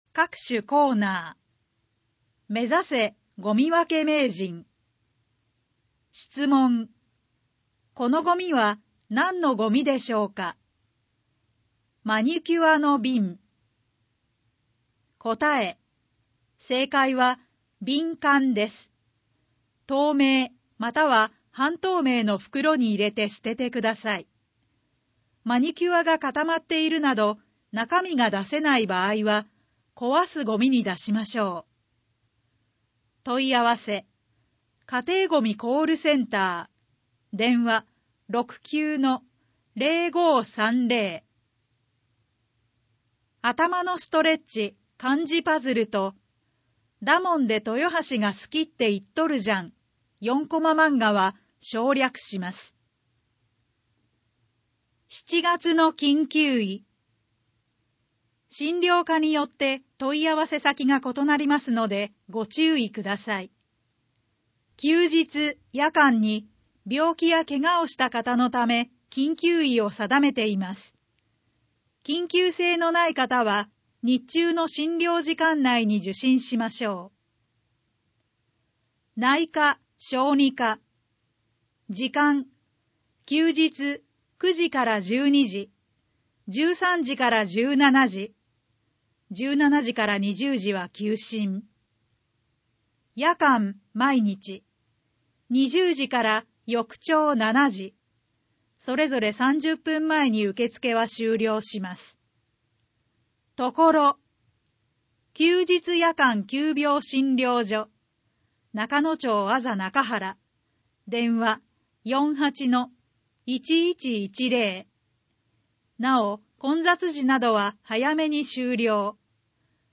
• 「広報とよはし」から一部の記事を音声でご案内しています。視覚障害者向けに一部読み替えています。